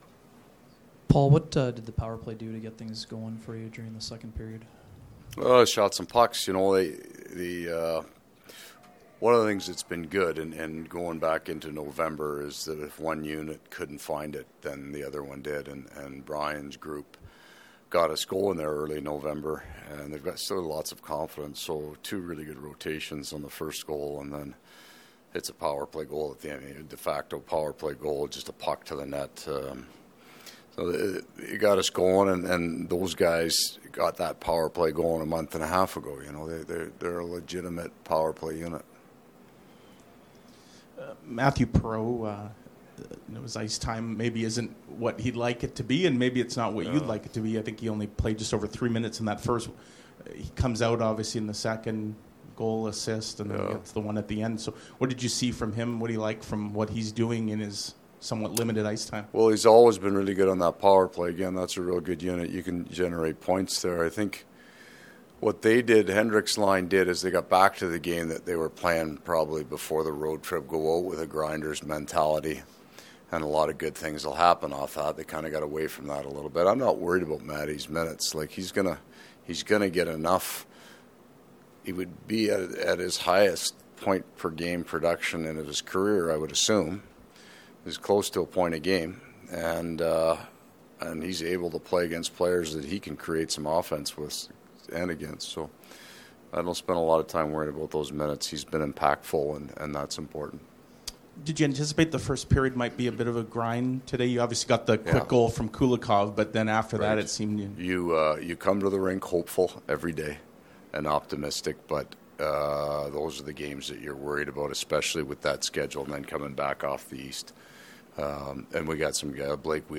Post-game from the Jets and Canucks dressing rooms as well as from Coach Maurice.